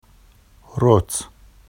[rot] n place, house